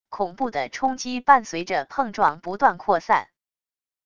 恐怖的冲击伴随着碰撞不断扩散wav音频